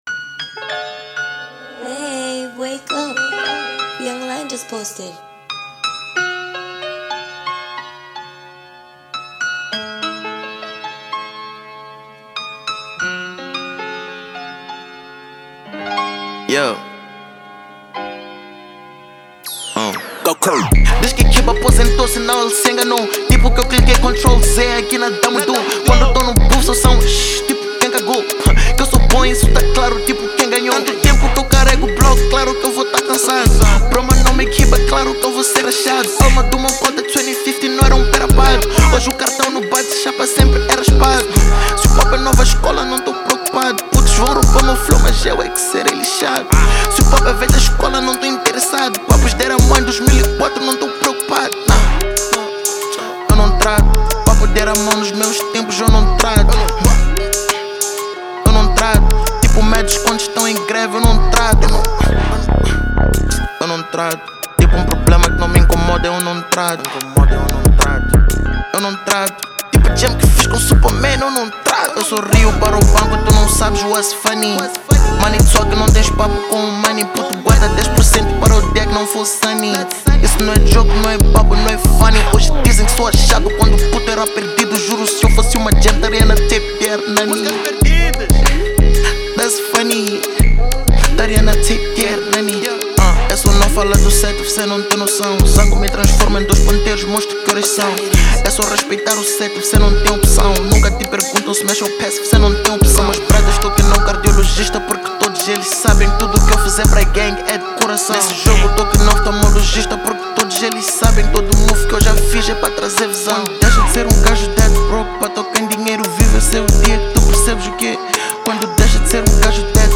• Gênero: Pop